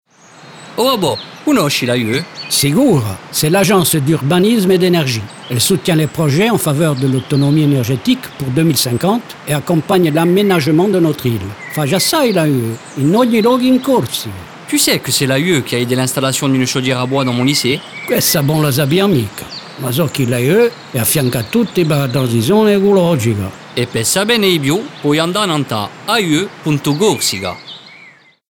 Comme les visuels, le message vise à présenter les missions principales de l'AUE. Ce dialogue entre un grand-père et son petit-fils marque le lien à maintenir entre le passé et l’avenir et notre volonté de transmettre à nos enfants une Corse où il fera encore bon vivre. Naturellement, le dialogue est bilingue pour témoigner l’attachement de l’AUE à la langue corse mais aussi pour sensibiliser les auditeurs qui pourront facilement s’identifier à cette famille.